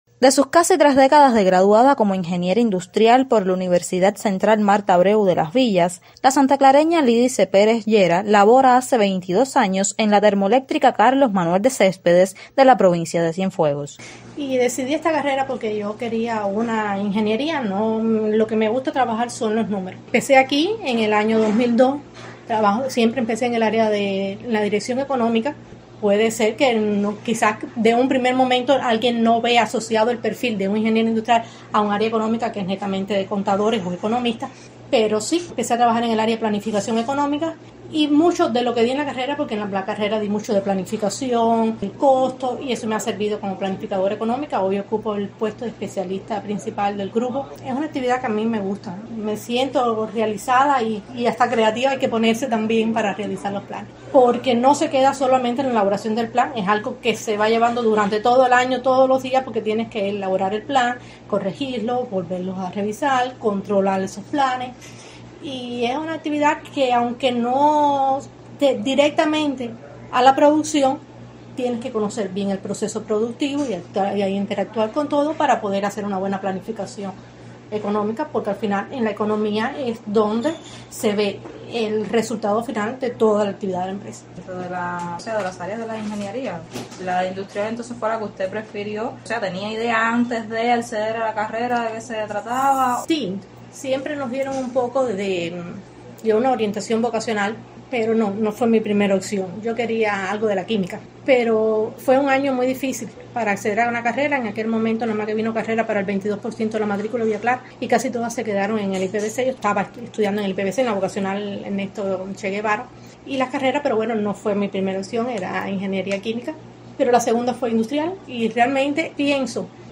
Cada 23 de junio se celebra el Día Internacional de la Mujer en la Ingeniería para concientizar a la sociedad sobre la importancia de contar con más mujeres en estas profesiones y resaltar su contribución al desarrollo socioeconómico. Escuche una entrevista